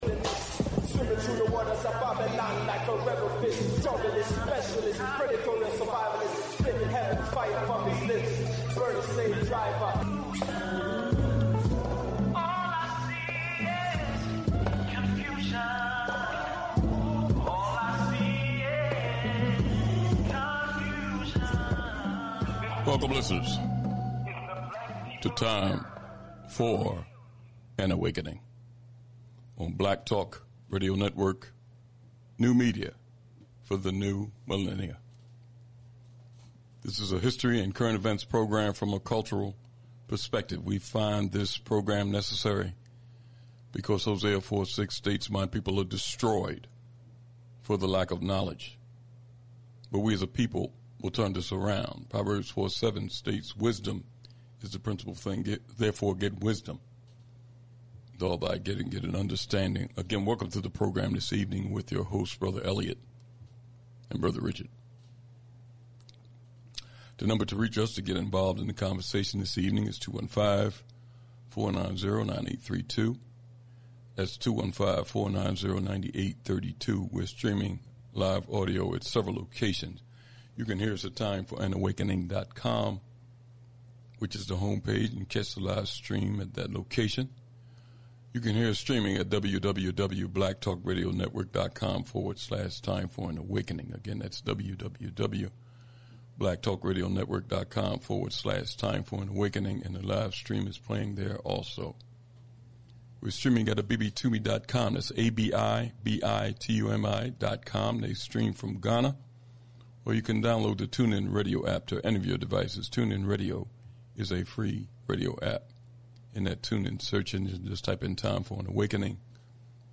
Always topics that affect Black people locally, nationally, and internationally. Information, insights, and dialogue from a Black Perspective.